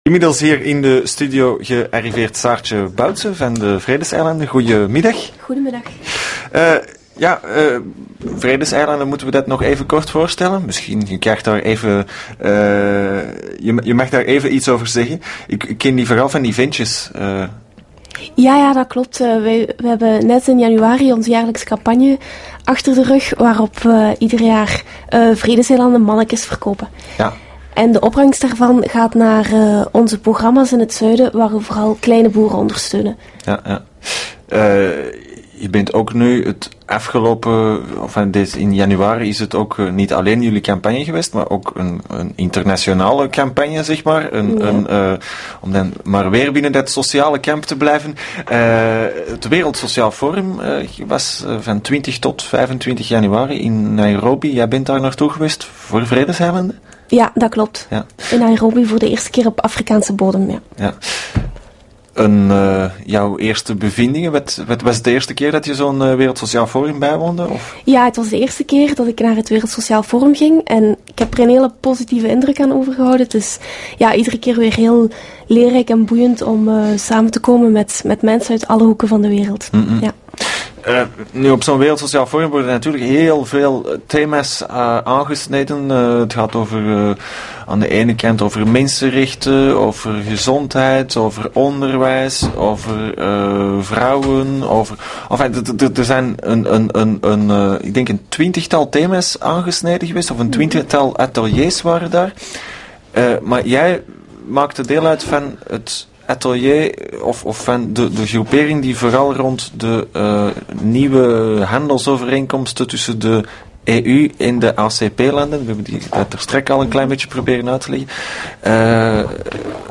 Het Wereld Sociaal Forum editie 2007 is afgelopen. Zeer uiteenlopende ateliers werden georganiseerd in Naïrobi, met één rode draad: een alternatief uitbouwen voor de neo-liberale politiek die vandaag de agenda van “de groten der aarde” beheerst. In de studio